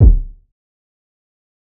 IN DA CLUB KICK (REVERB).wav